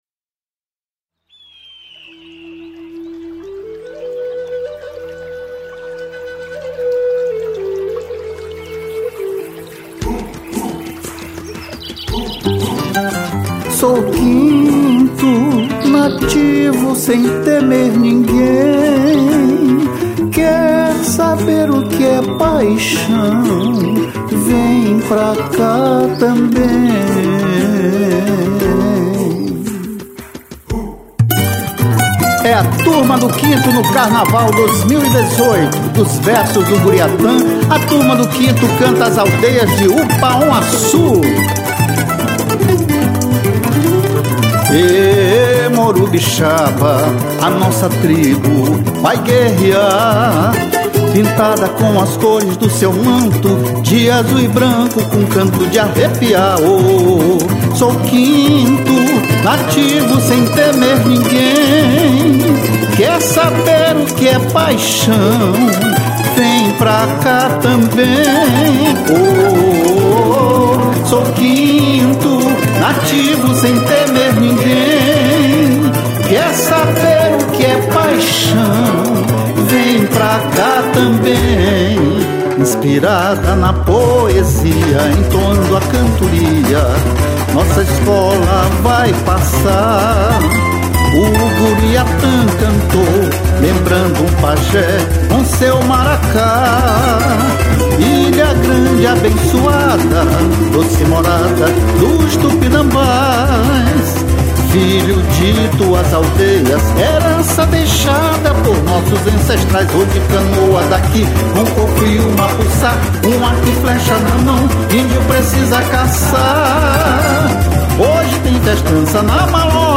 samba-enredo
introdução temática de cunho indígena